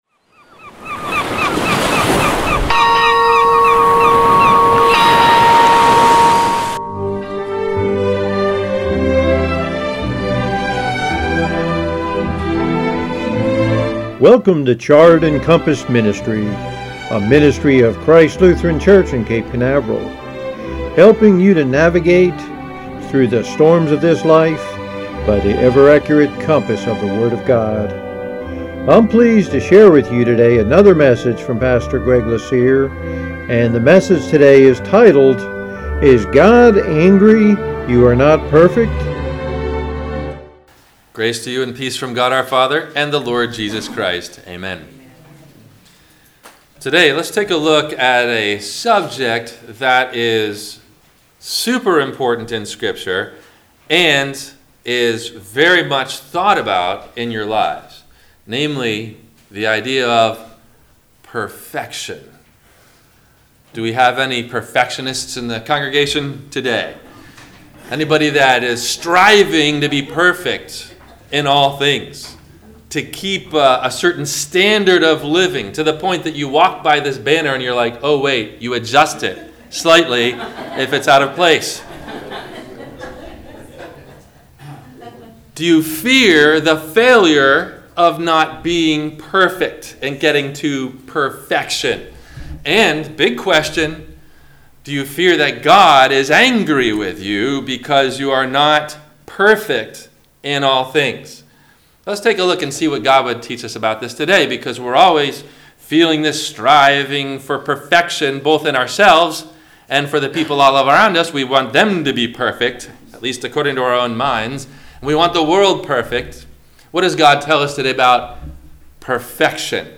Is God Angry You Are Not Perfect? – WMIE Radio Sermon – February 17 2020
No Questions asked before the Sermon message.